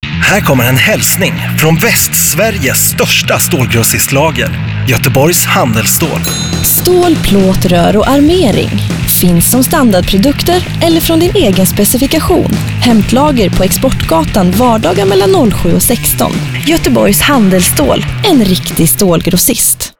Lyssna på vår radioreklam